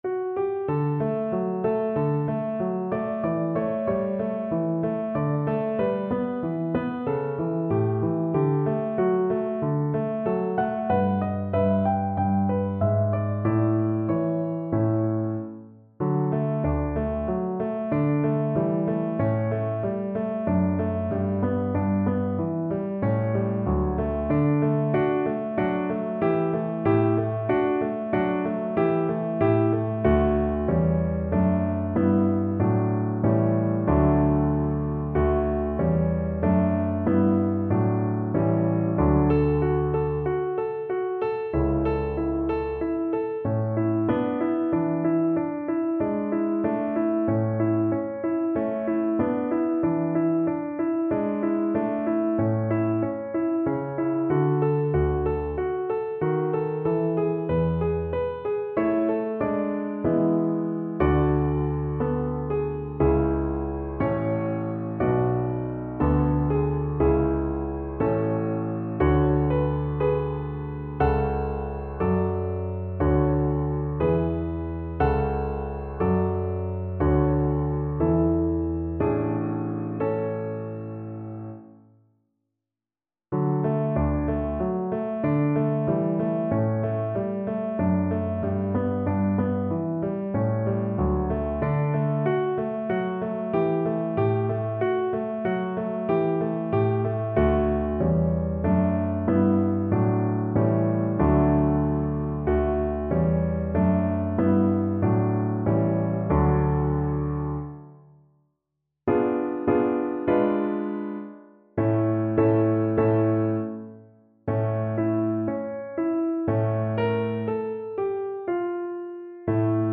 Allegretto = 94
4/4 (View more 4/4 Music)
Classical (View more Classical Mezzo Soprano Voice Music)